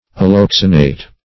Search Result for " alloxanate" : The Collaborative International Dictionary of English v.0.48: Alloxanate \Al*lox"a*nate\, n. (Chem.) A combination of alloxanic acid and a base or base or positive radical.